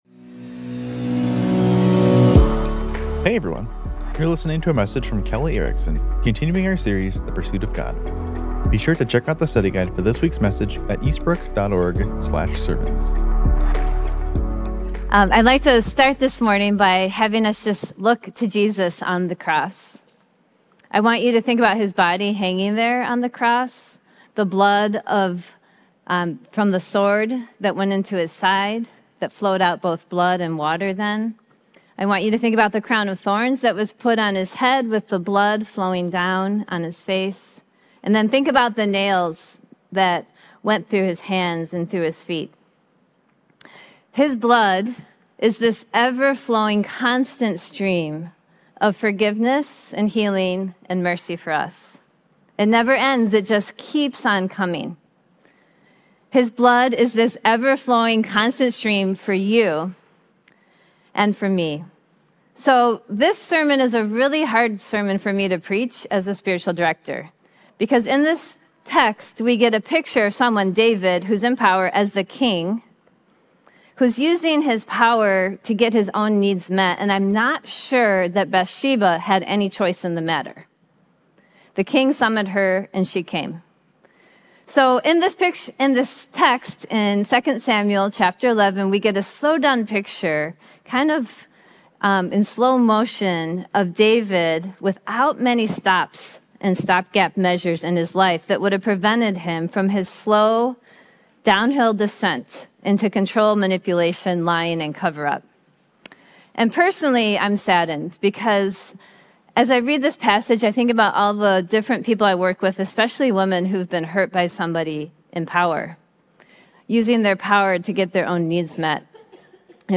Sermon Archive – Eastbrook Church